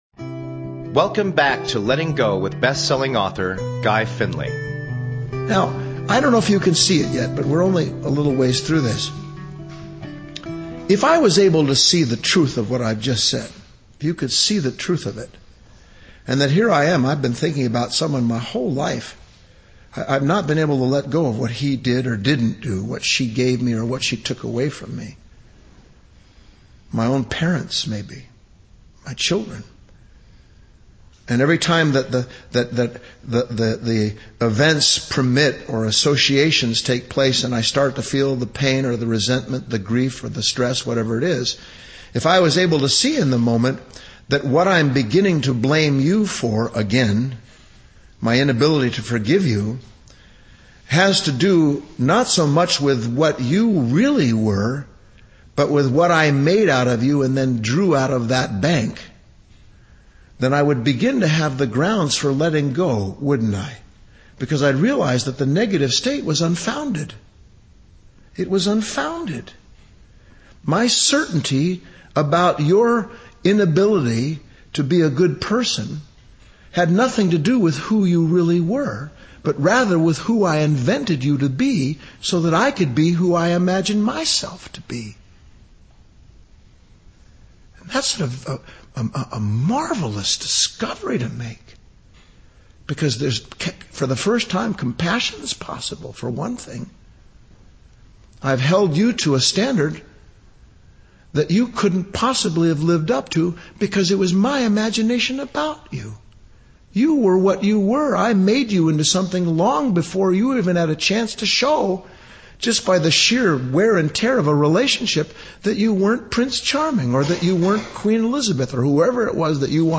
Subscribe Talk Show Letting Go with Guy Finley Show Host Guy Finley GUY FINLEY’s encouraging and accessible message is one of the true bright lights in our world today.